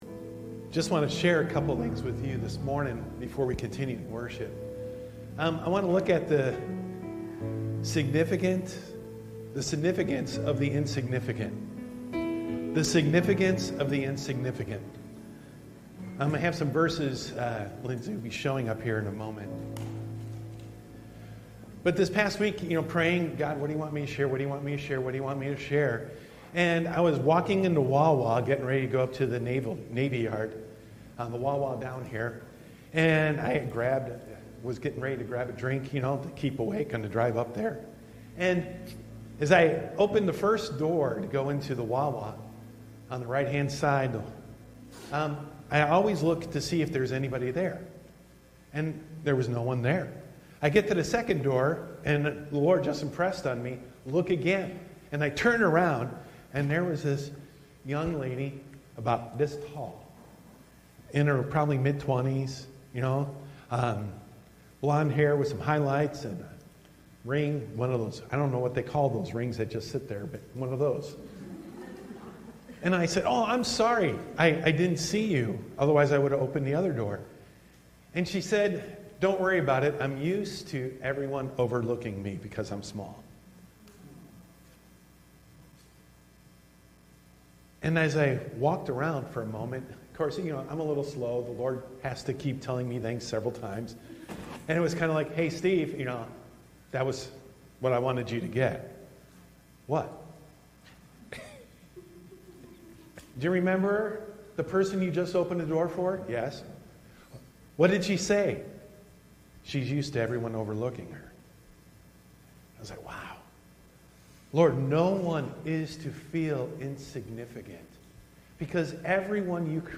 This message was presented as part of our monthly Encounter Service.